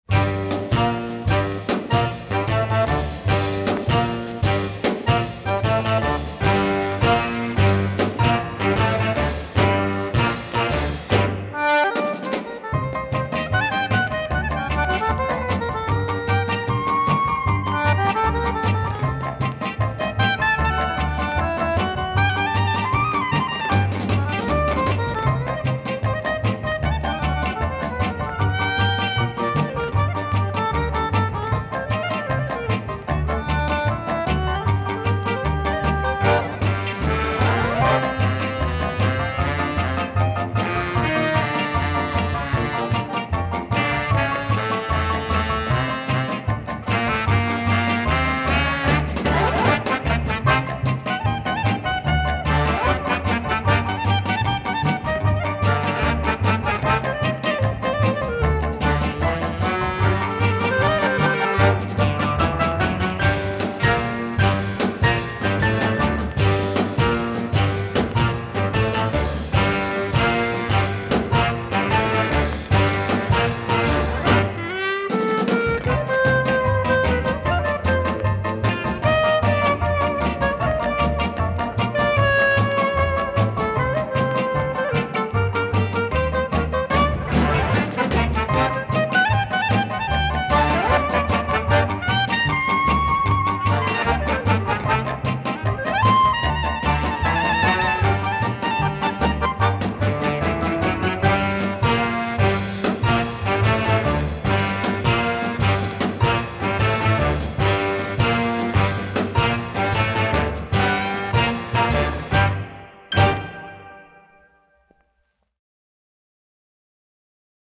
нежные твисты